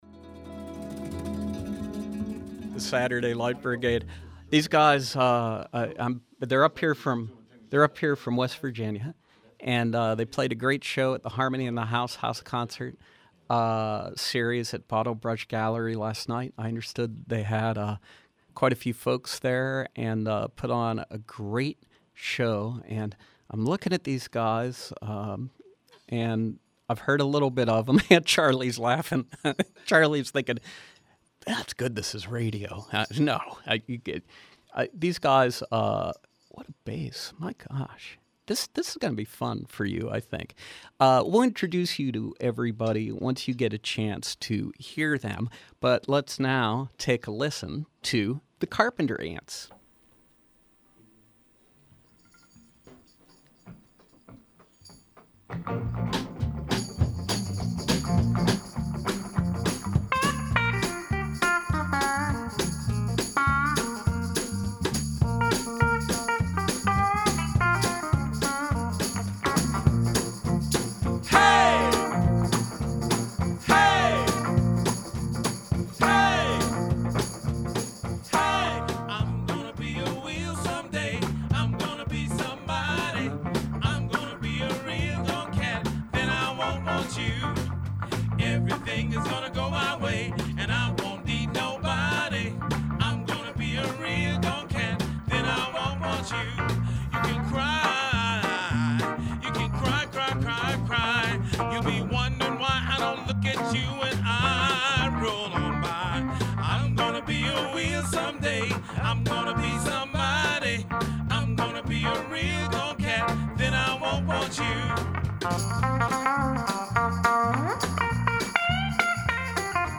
combine old-style R&B with gospel, funk, soul and country